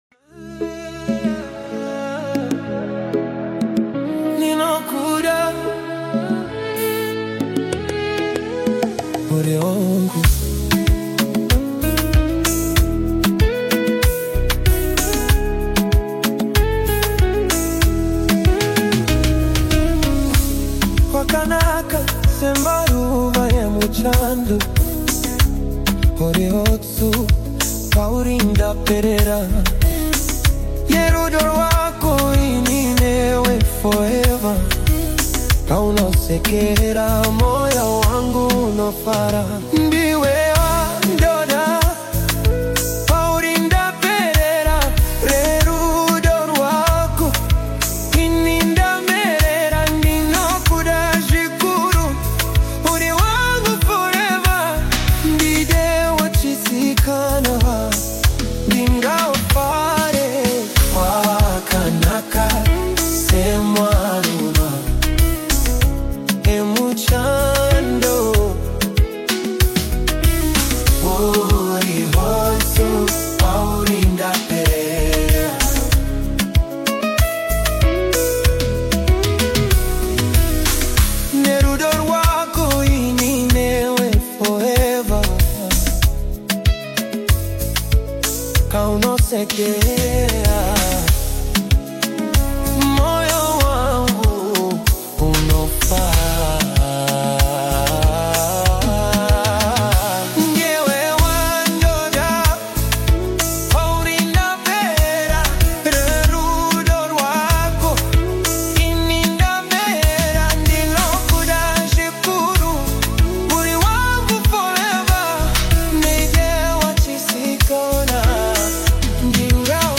AudioZimbabwe Music
creative Afro-Pop reinterpretation